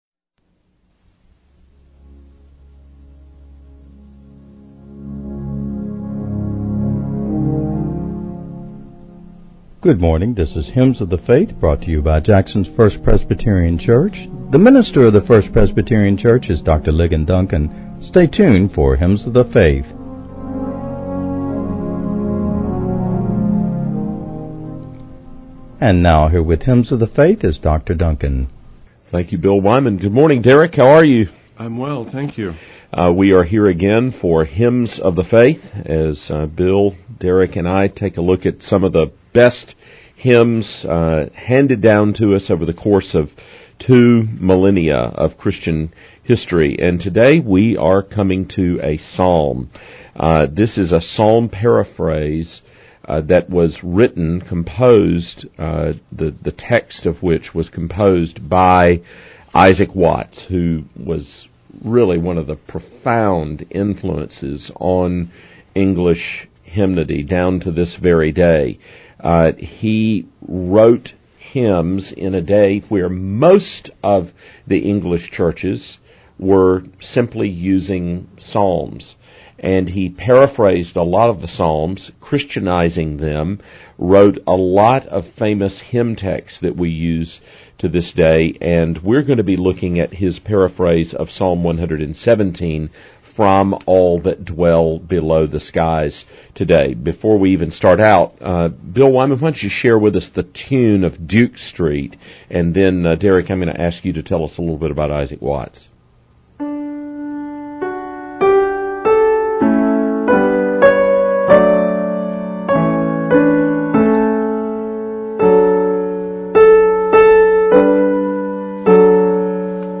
[ Plays hymn. ]